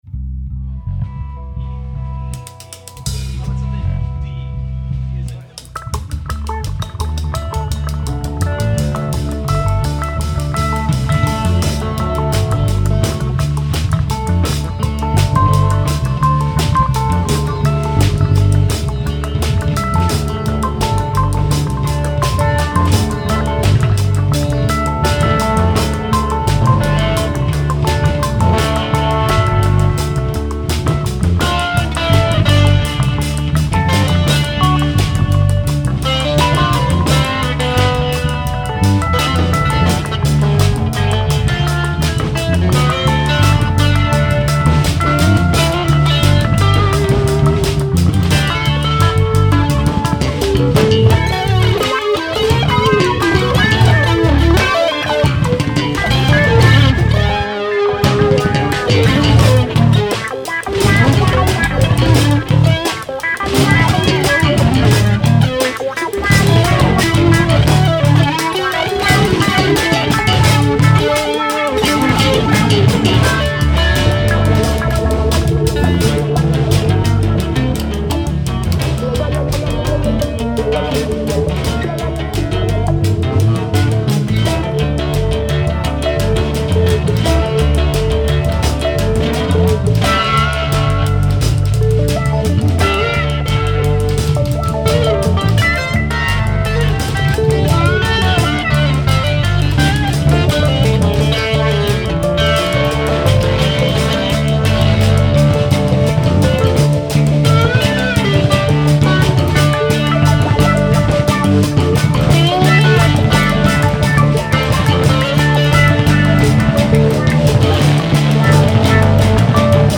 Instrumental only so far
Bass
Guitar